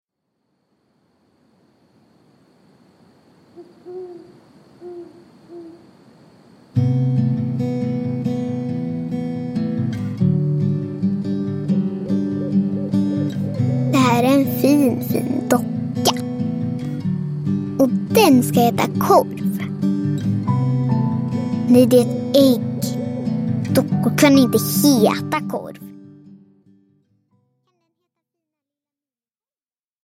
Stora faran – Ljudbok – Laddas ner